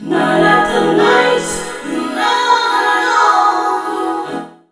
NIGHTVOCOD-R.wav